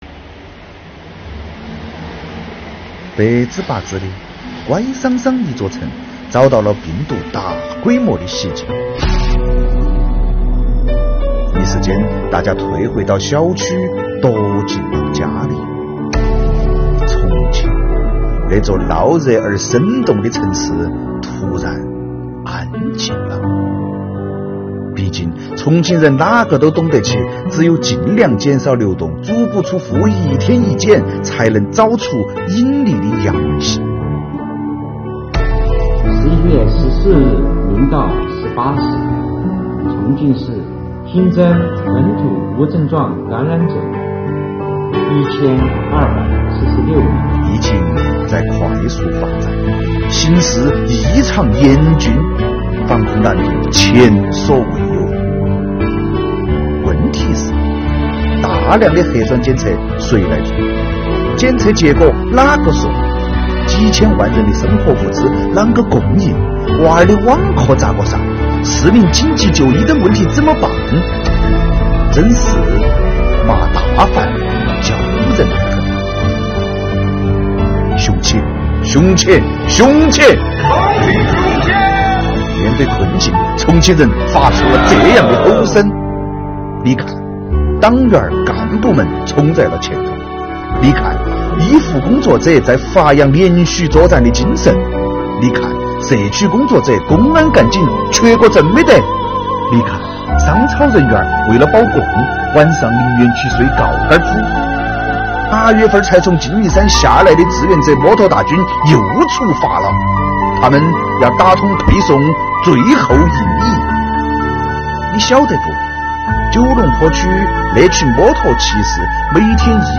（方言版）